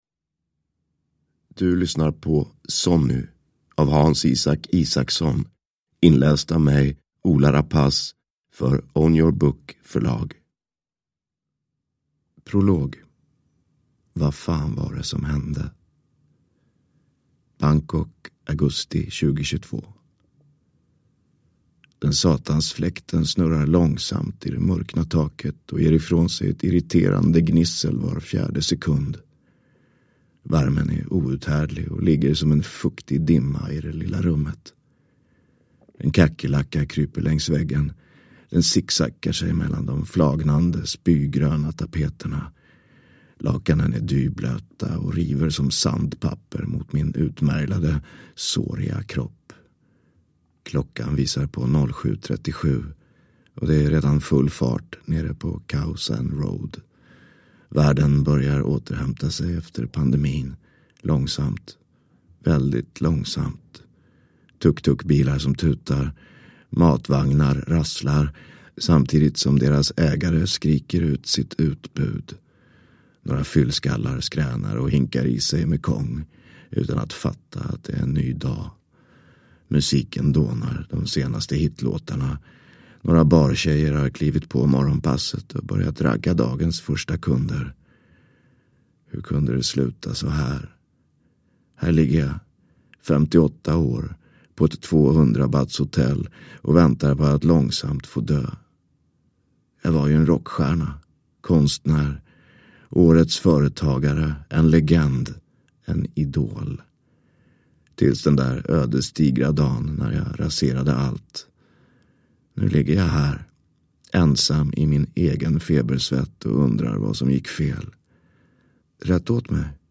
Sonny – Ljudbok
Uppläsare: Ola Rapace